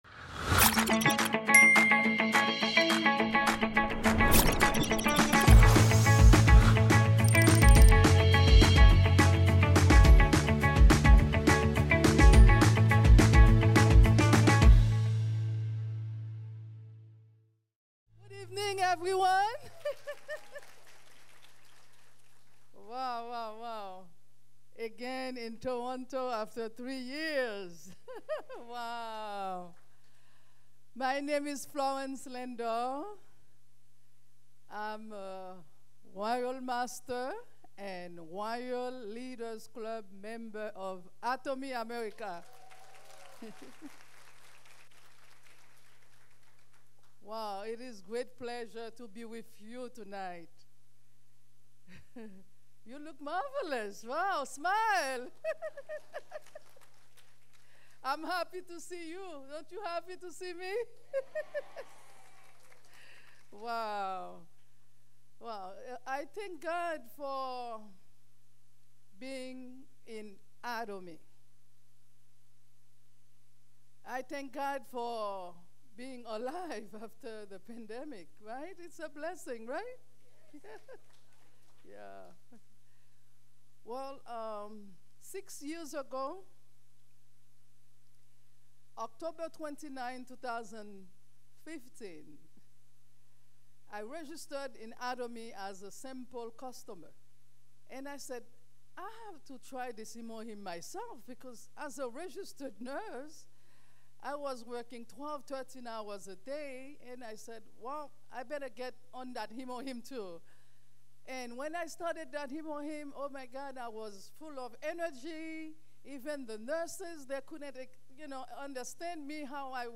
Toronto Seminar